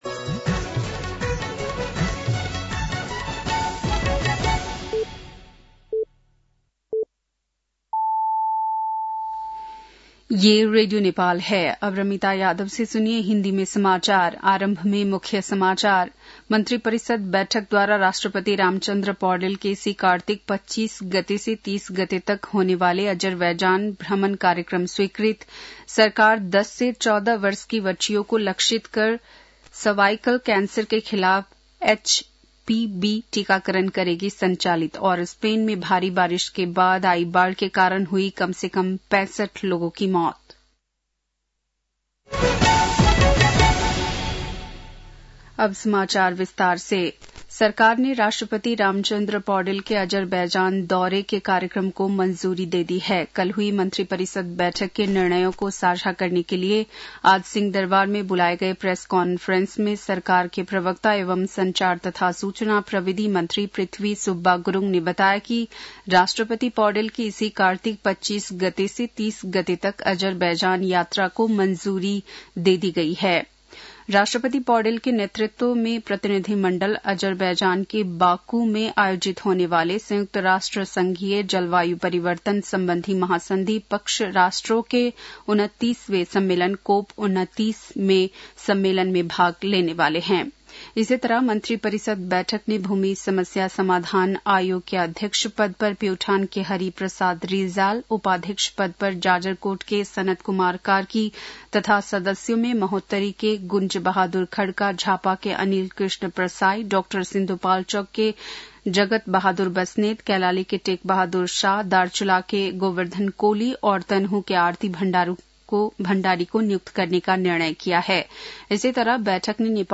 बेलुकी १० बजेको हिन्दी समाचार : १५ कार्तिक , २०८१
10-pm-news-7-14.mp3